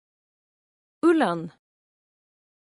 Amazon AWS ( Scottish [!] pronunciation).